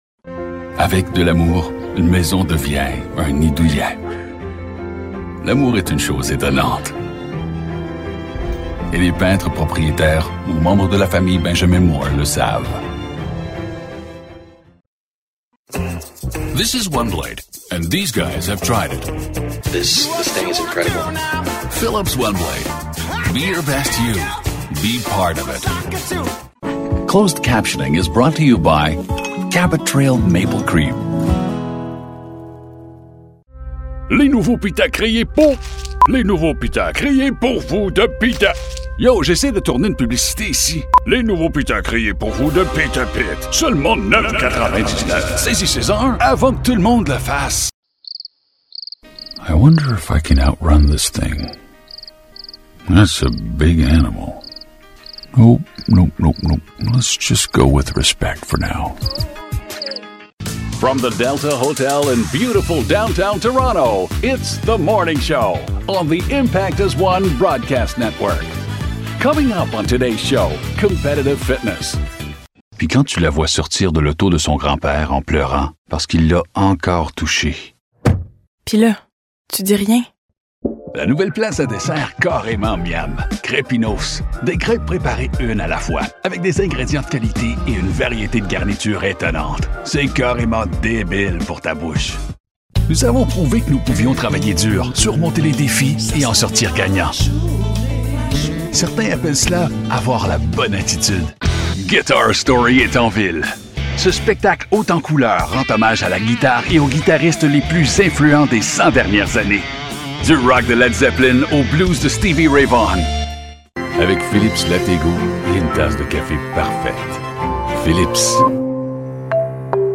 Neutral English and French Canadian - Bilingual commercial demo